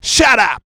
SHUT UP.wav